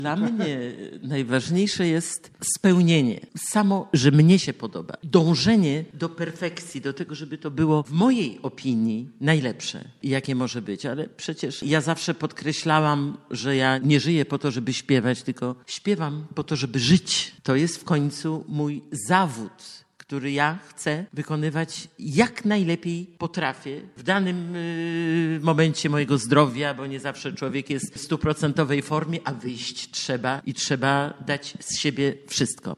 W majowej audycji Cafe "Muza" z 2022 roku w radiowej Dwójce mówiła, że śpiewa by żyć: